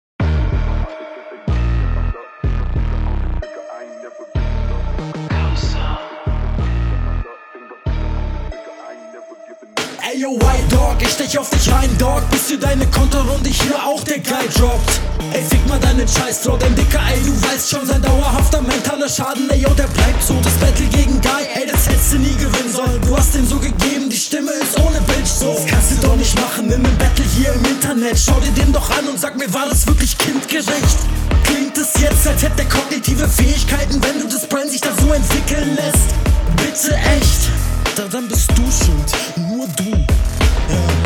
beat dope